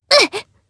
Sonia-Vox_Damage_jp_02.wav